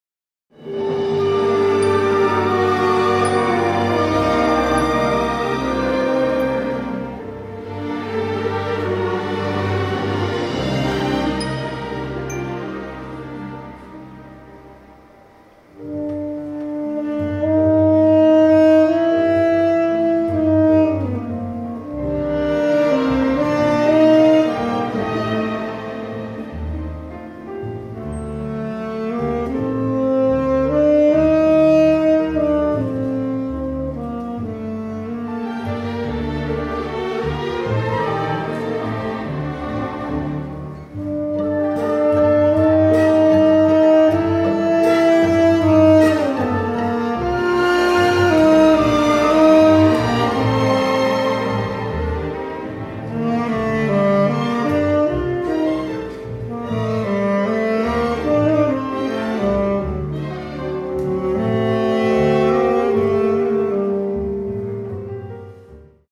Scores for symphony orchestra